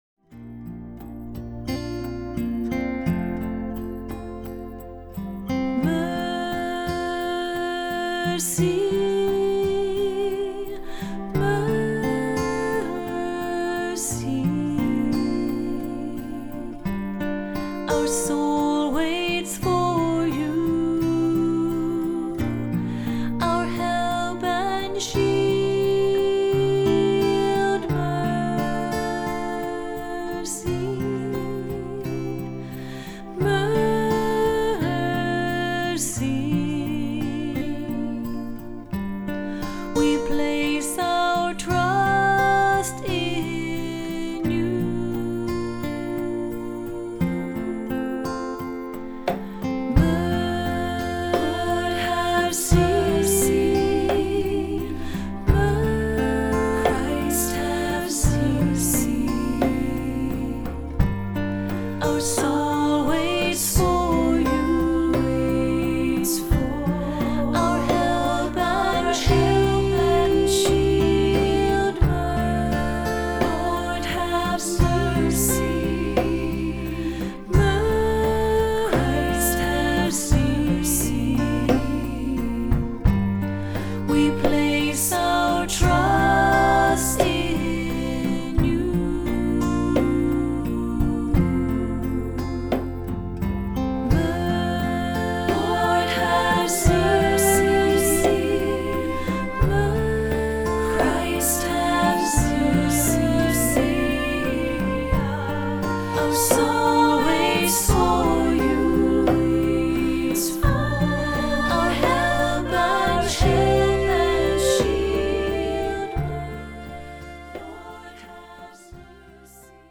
Voicing: SSA; Assembly